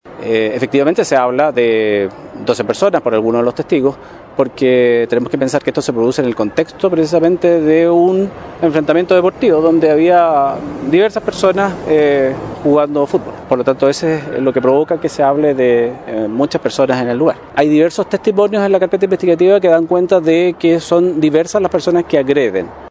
El fiscal Claudio Ciudad Cueto, también confirmó que se habla de 12 personas involucradas, según testigos en el lugar.
med-cuna-fiscalia.mp3